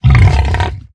latest / assets / minecraft / sounds / mob / hoglin / angry4.ogg
angry4.ogg